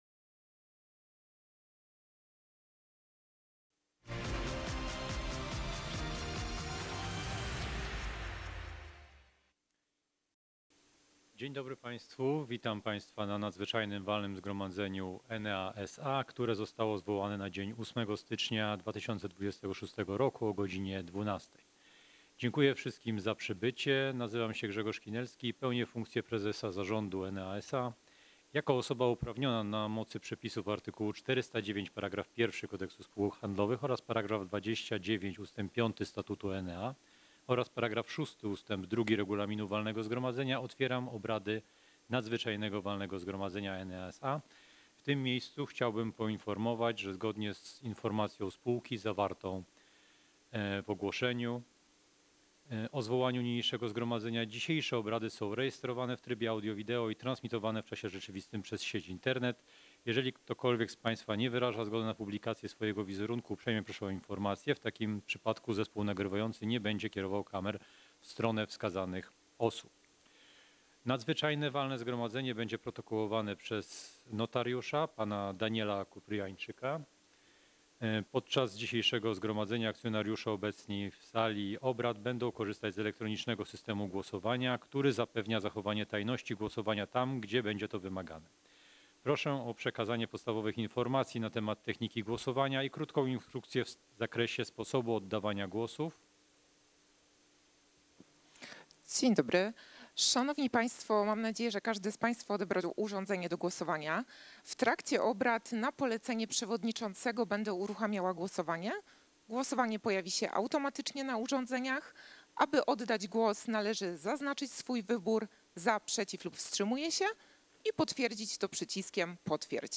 Nagrania zw Zgromadzeń Akcjonariuszy.